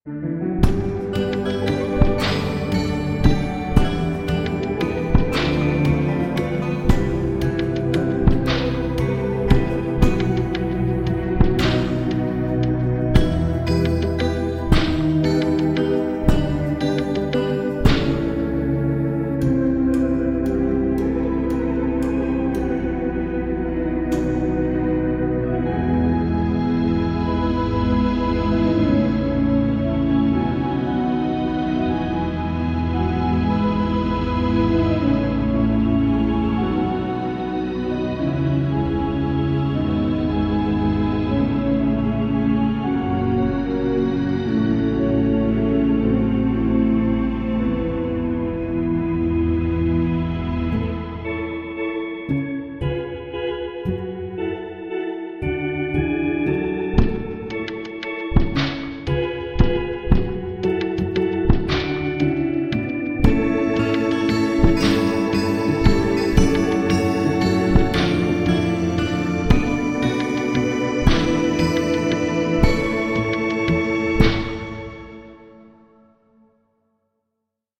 我们在我们的 Sand Creek 工作室里录制了它的最后一息，用两个麦克风位置：近距离和房间。Sandy Creek Organ 包含了六种不同的延音类型（其中两种带有旋转的 Leslie 扬声器），每种都有循环和释放，以及各种音效。